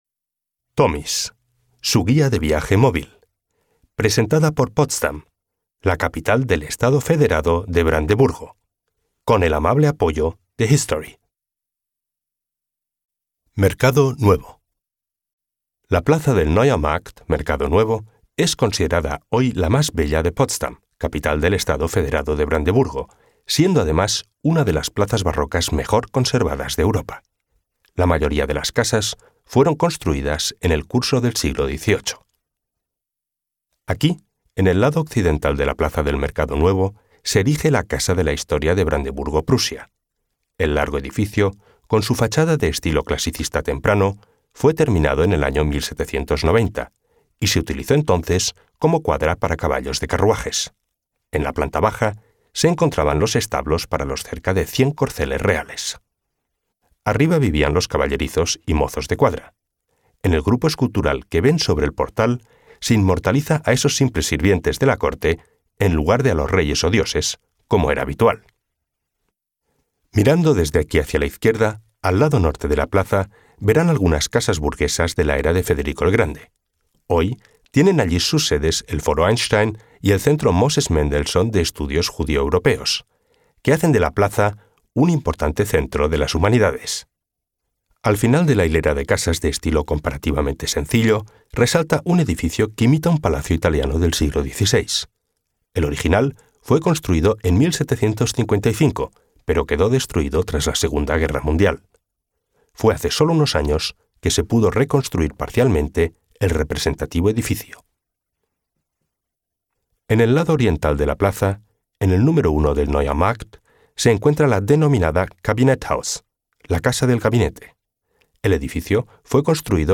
Los audioreportajes tienen una duración de dos a tres minutos.